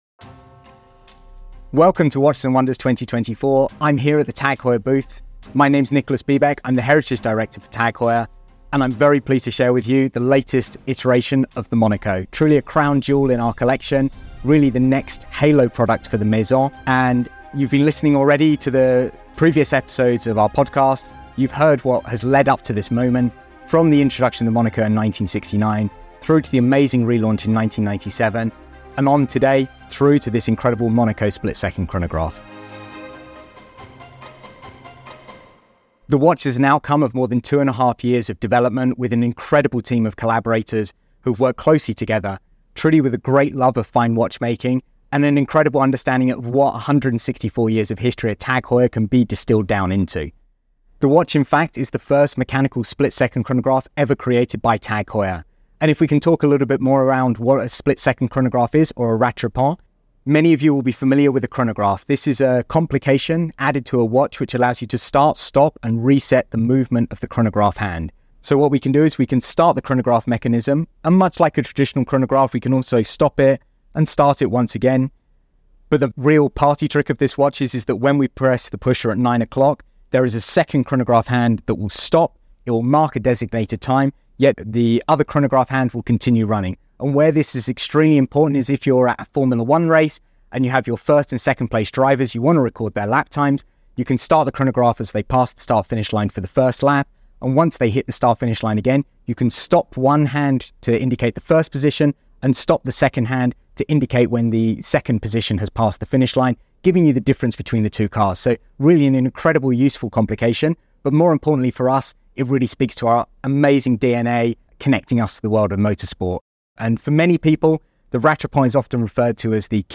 2024: the Monaco Split-Seconds Chronograph (Live from Watches & Wonders 2024)